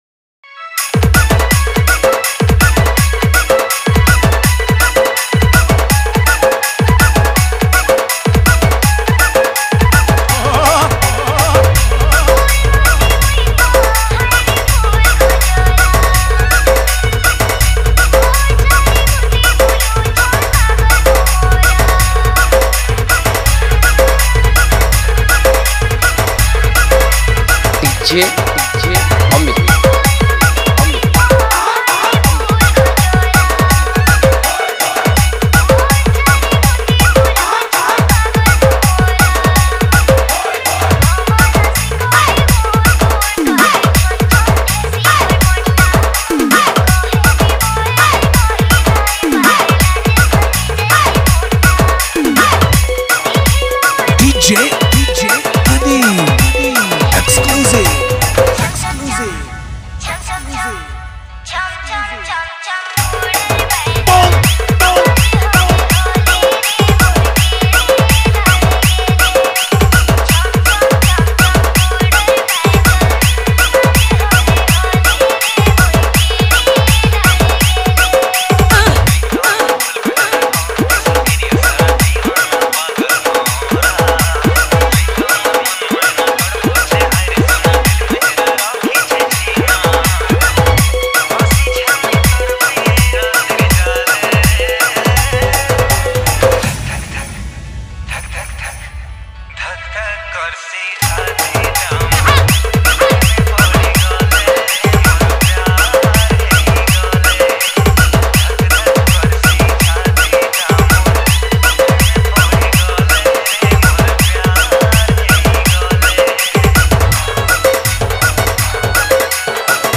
Category:  Sambalpuri Dj Song 2024